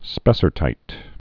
(spĕsər-tīt) also spes·sar·tine (-tēn)